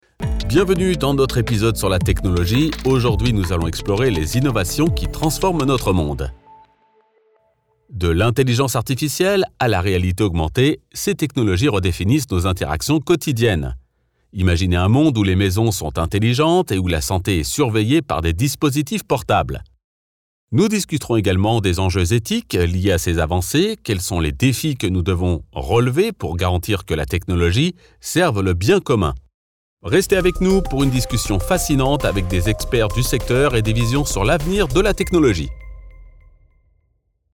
Podcast Voice Overs
Adult (30-50) | Older Sound (50+)